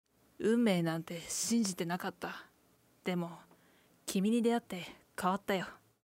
ボイス
女性